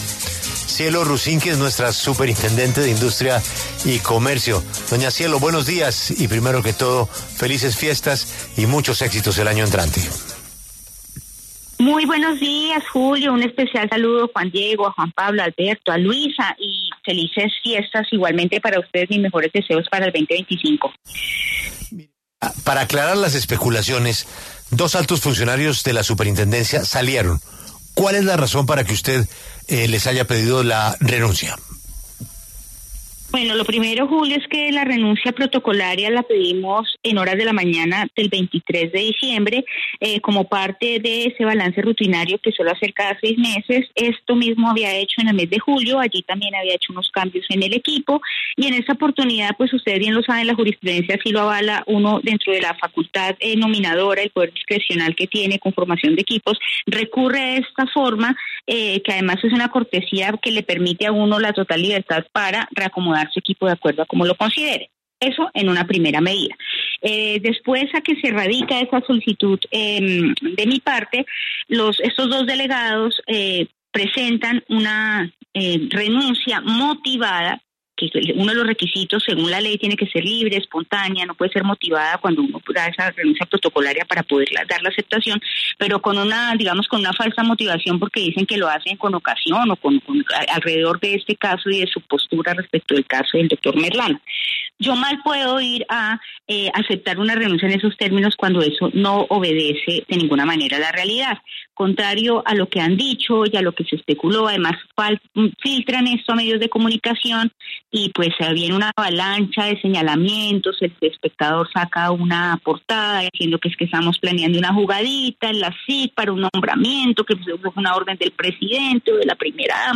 Cielo Rusinque, superintendente de Industria y Comercio, se refirió en La W a la polémica por la salida de dos altos funcionarios de la entidad.